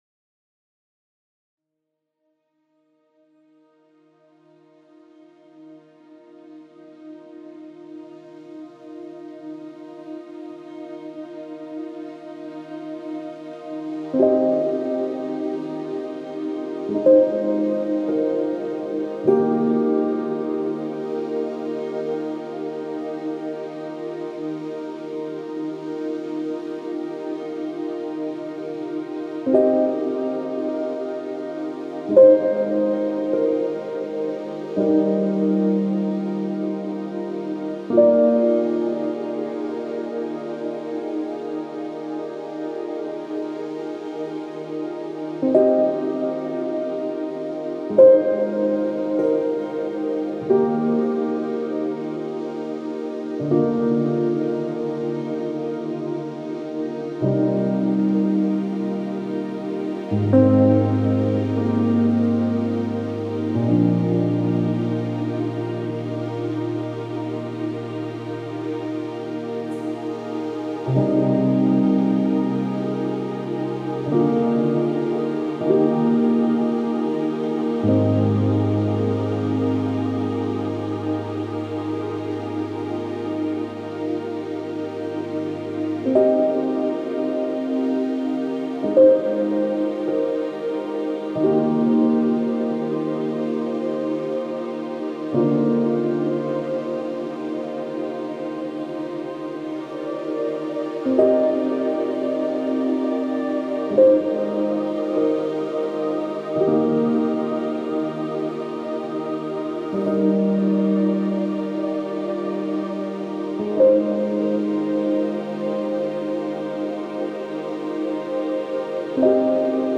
موسیقی کنار تو
سبک الهام‌بخش , پیانو , موسیقی بی کلام